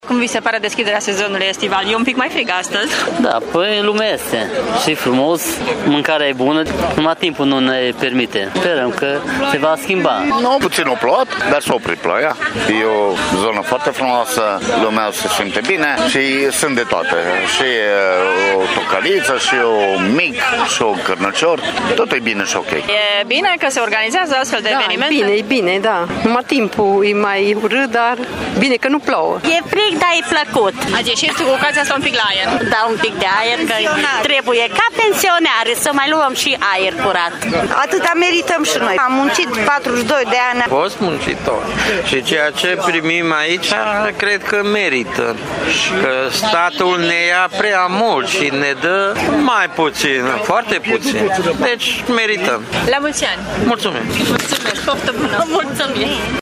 Târgumureșenii s-au bucurat de bucatele bune și de berea pe care, spun că le merită de Ziua Internațională a muncii, mai ales că au muncit zeci de ani: